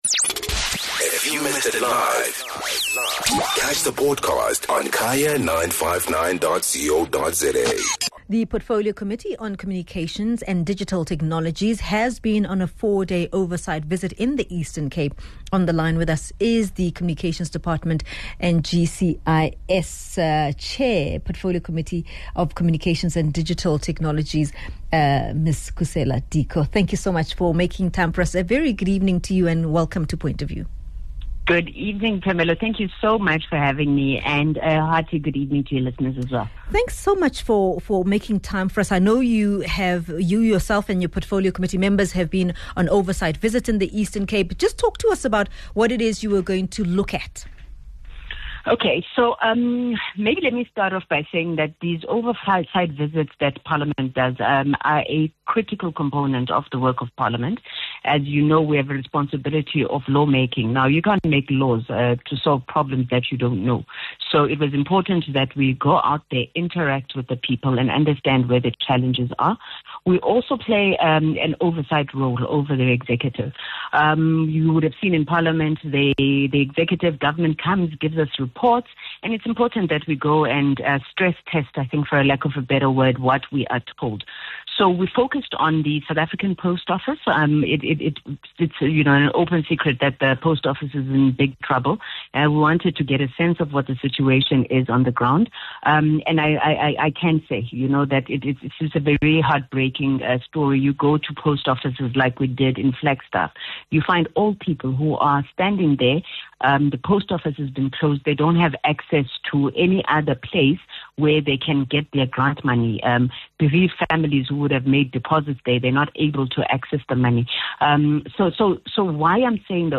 speaks to the Portfolio Chairperson, Khusela Diko.